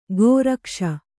♪ gōrakṣa